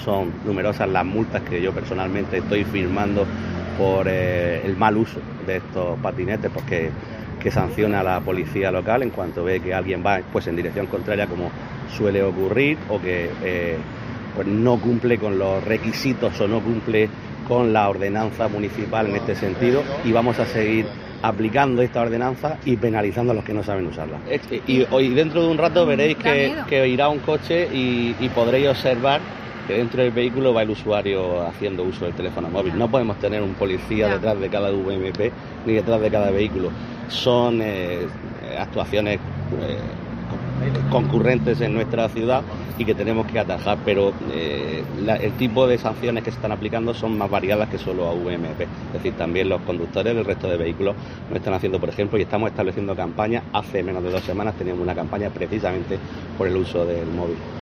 Juan Miguel Bayonas, concejal de Seguridad Ciudadana del Ayuntamiento de Lorca
Sobre la seguridad vial relacionada con este tipo de vehículos, COPE, le ha preguntado al edil de Seguridad Ciudadana del Ayuntamiento de Lorca, Juan Miguel Bayonas, asegurando que desde el consistorio "son numerosas las multas que estamos firmando por el mal uso de estos vehículos".